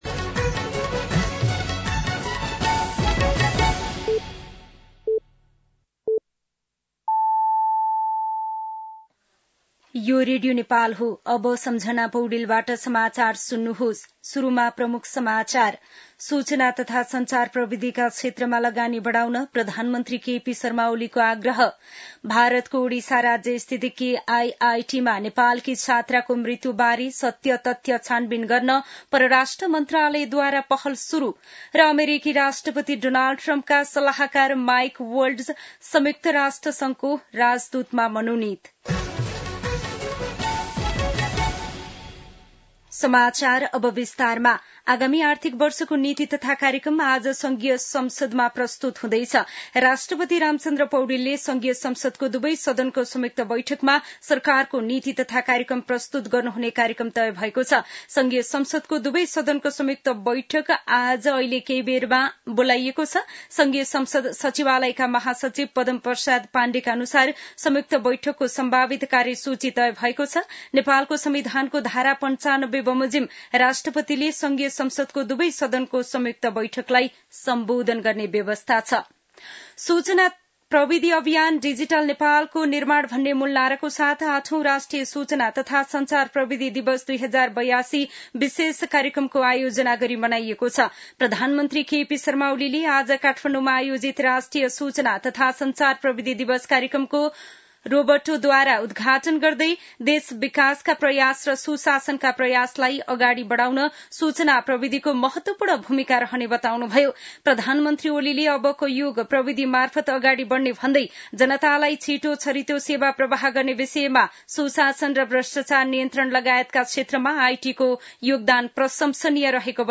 दिउँसो ३ बजेको नेपाली समाचार : १९ वैशाख , २०८२
3-pm-news-.mp3